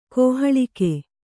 ♪ kōhaḷike